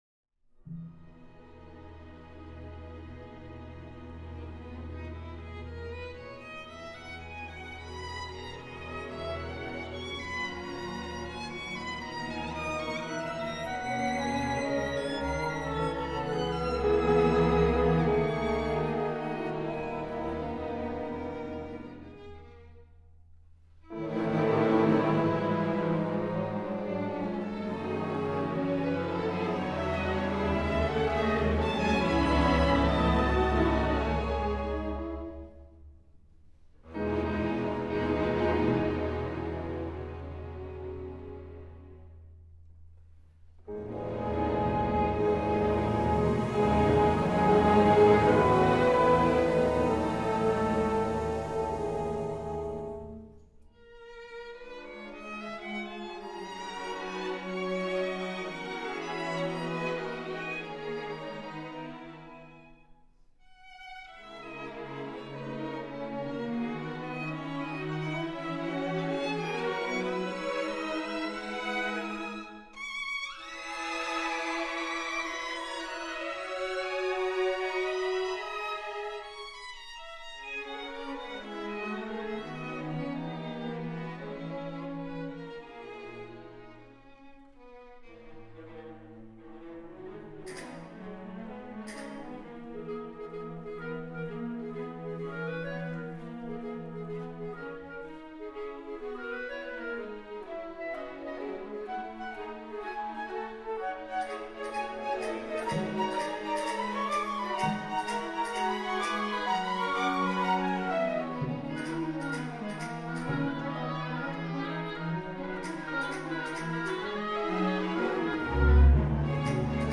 Harp, Piano, Strings.